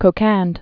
(kō-kănd) or Qu·qon (k-kôn, -ôn)